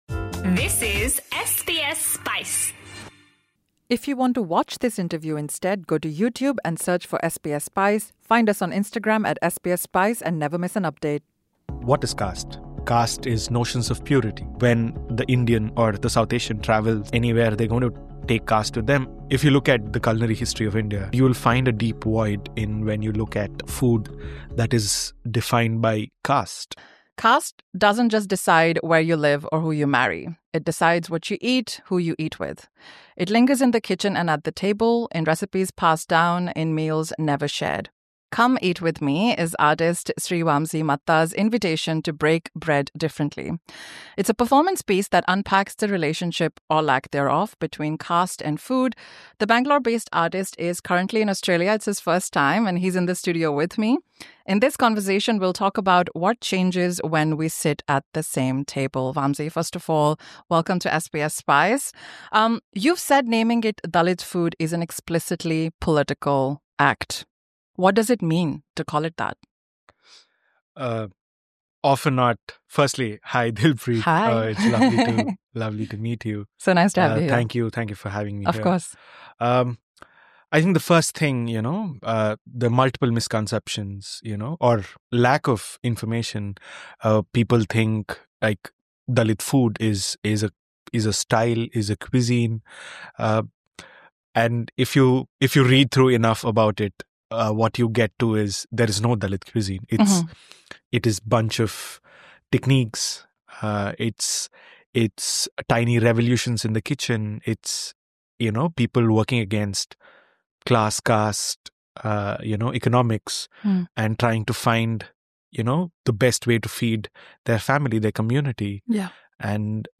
Watch this interview on YouTube: Presented by Campbelltown Arts Centre and H_ME W_RK.